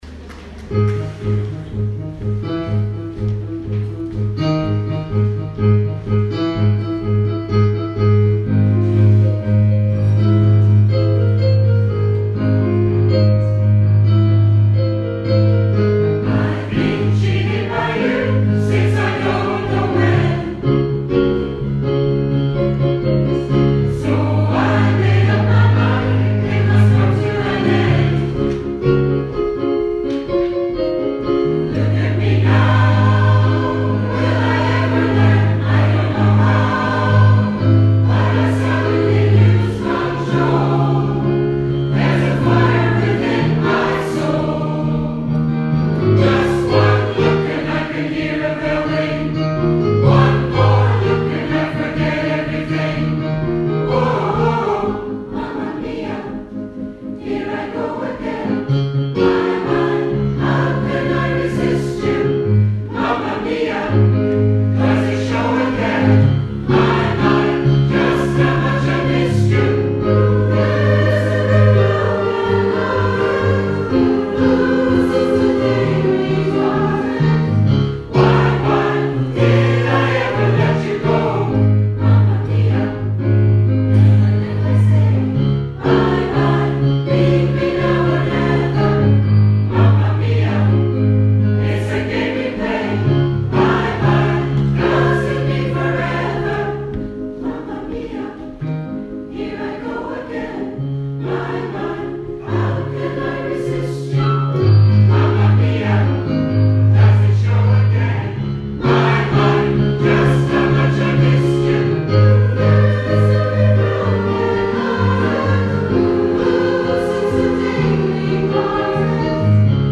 A great crowd turned out for the Guild social coffee evening with the Community Choir entertaining us with  a selection of popular songs.
Music from the shows and favourite films, with some scottish extras brought back memories for many of the audience, with a few singing along to those golden favourites.